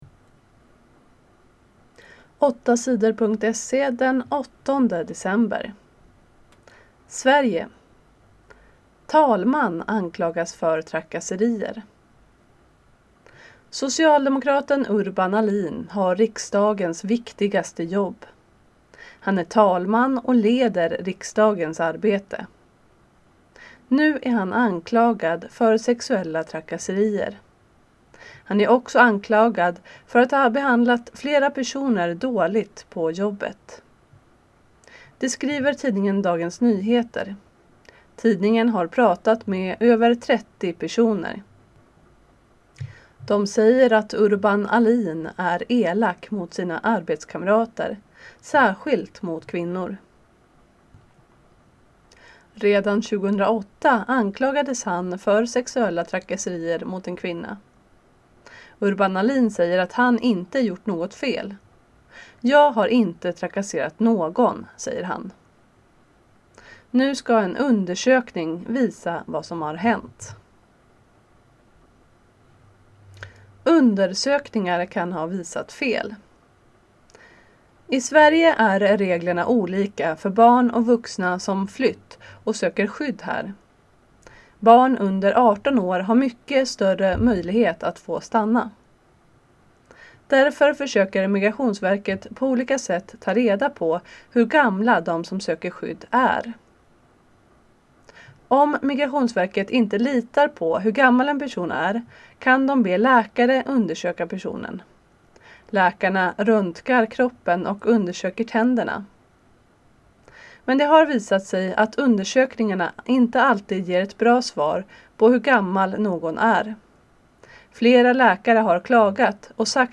Inlästa nyheter den 8 december 2017